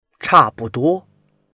cha4buduo1.mp3